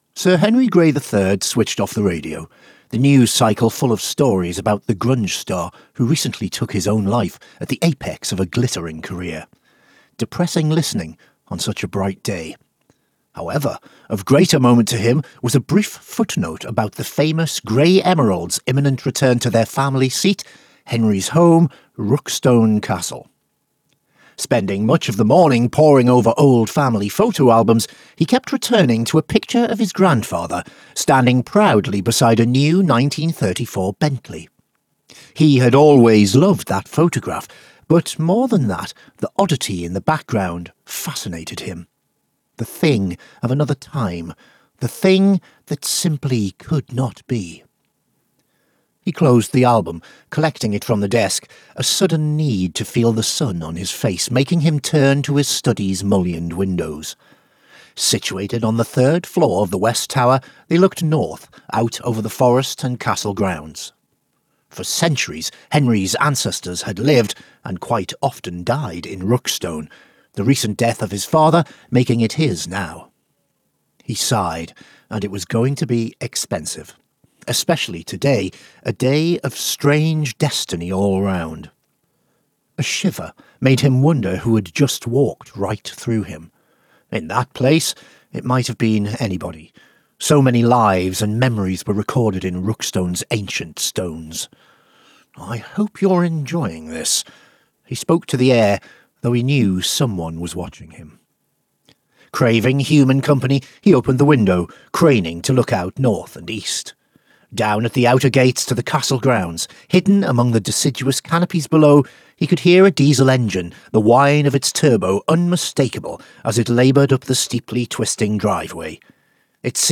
--- audiobook ---
Rookstone-Promo.mp3